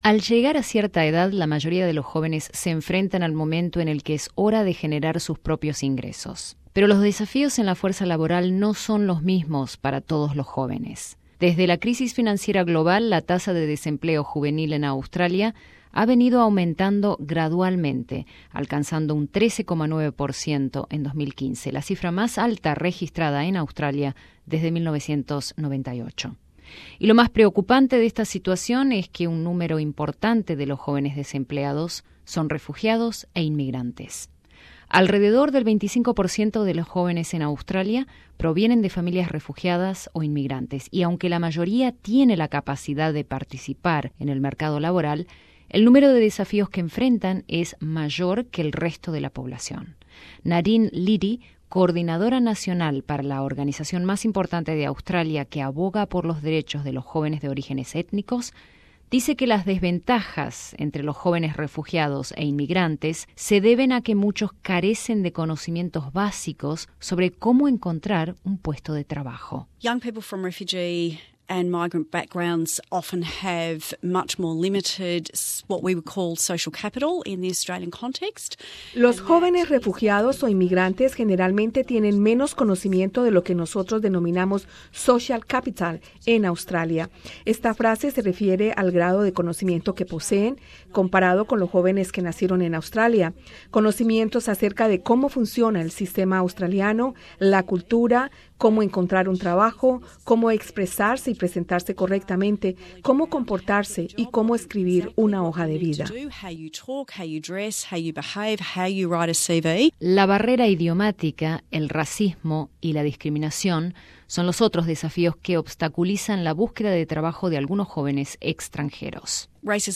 ¿Cuáles son las principales dificultades que enfrentan estos jóvenes y qué tipo de apoyo necesitan? Escucha nuestro informe.